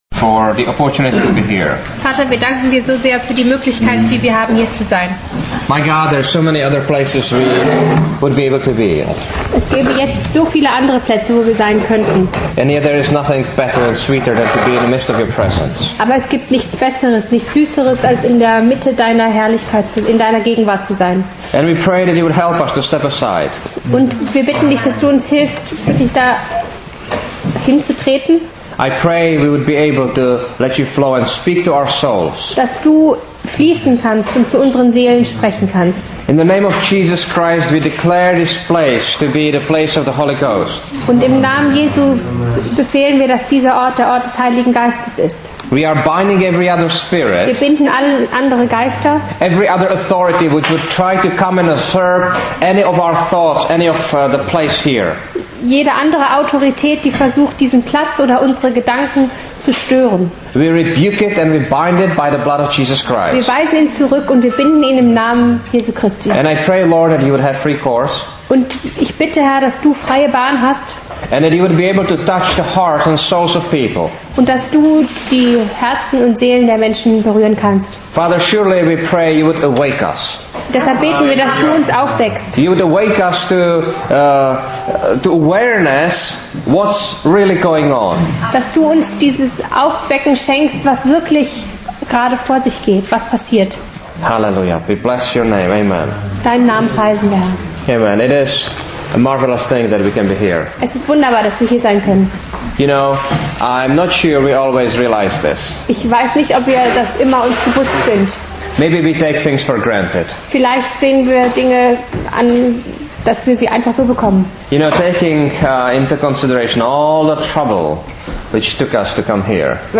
[The following messages include German translation.]